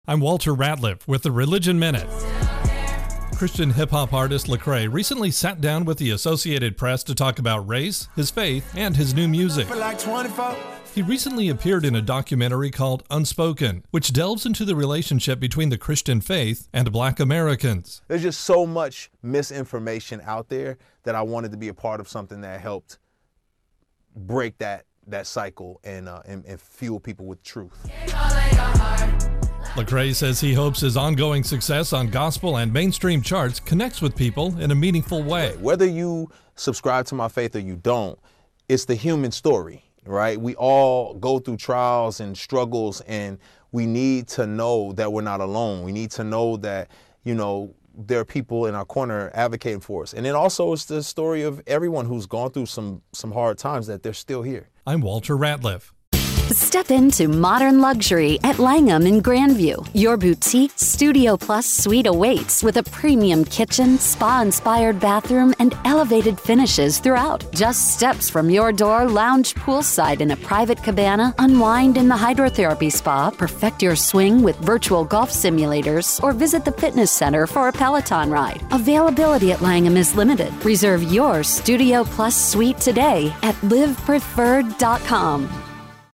AP correspondent
with an interview with Christian hip-hop performer LeCrae.